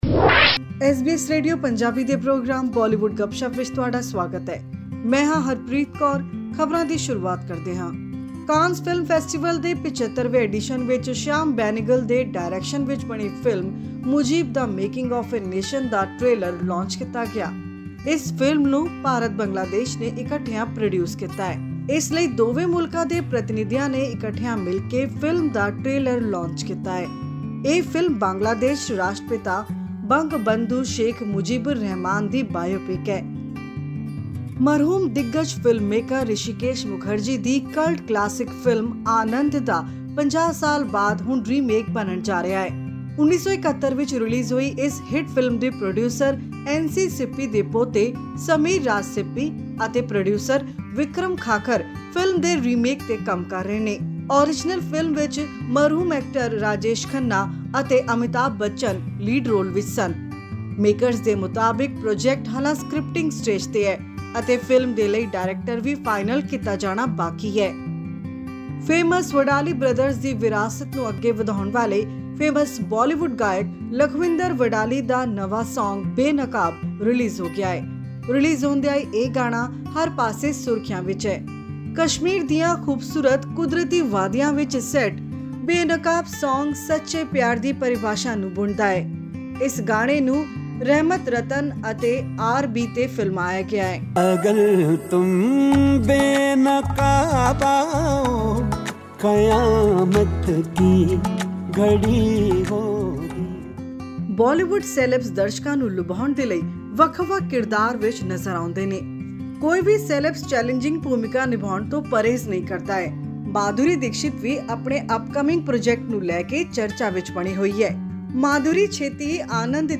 Listen to this audio report for more details...